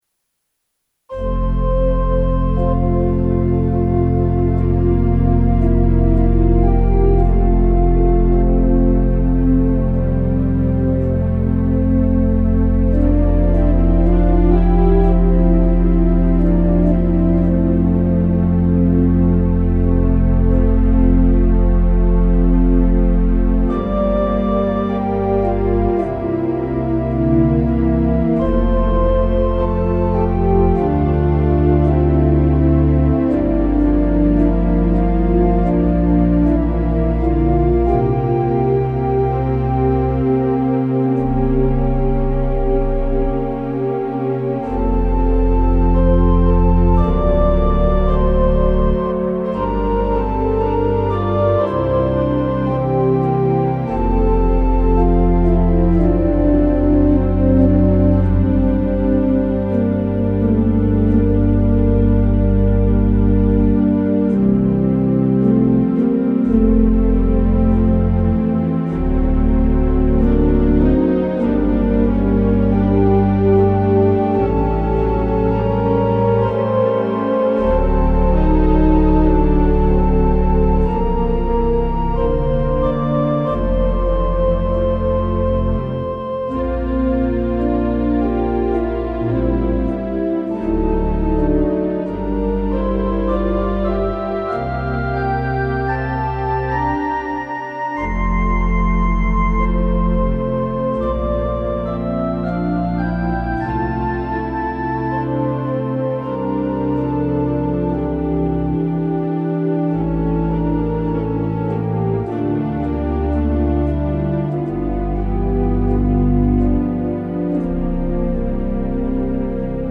Organ Interludes Audio Gallery